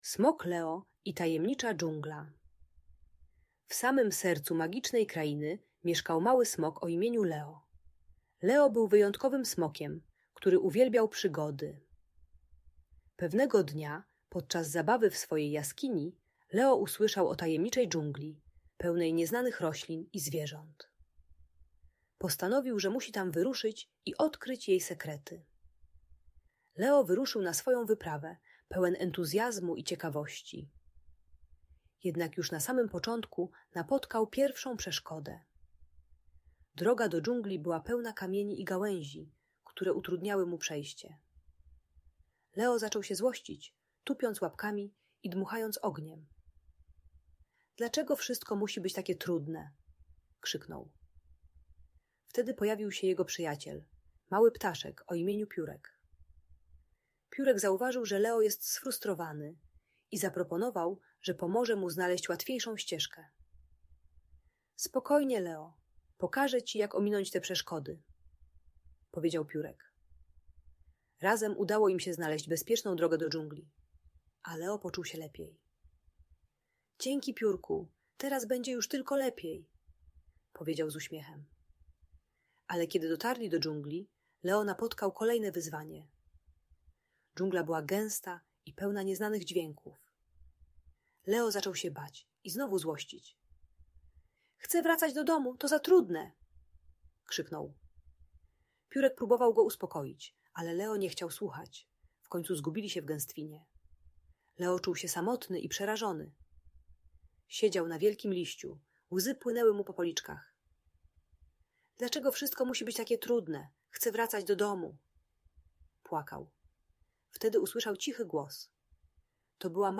Smok Leo uczy się techniki głębokiego oddechu i szukania pomocy zamiast krzyczeć i tupać. Audiobajka o radzeniu sobie ze złością i frustracją.